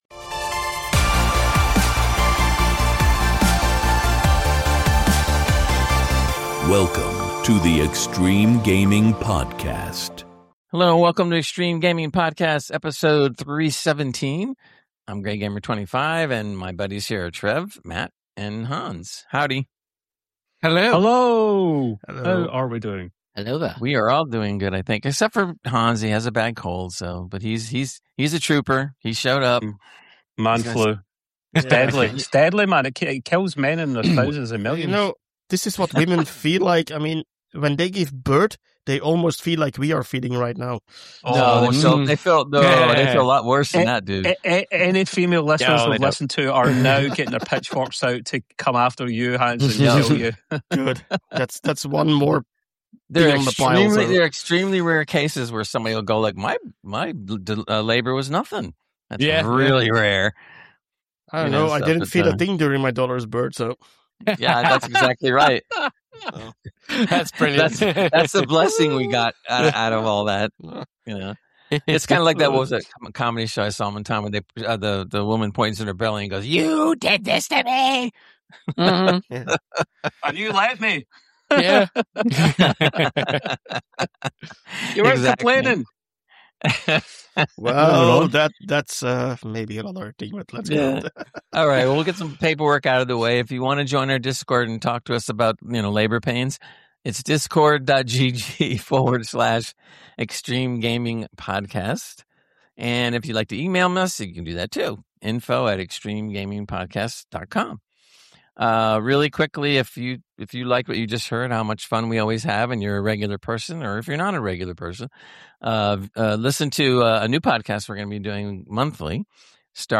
In this podcast we are a group of everyday gamers who dont take life to seriously, we like to have a laugh and talk about the games and tech that we love across all gaming platforms. We cover gaming/hardware news, games weve played and our own general views whilst often going off on wild tangents.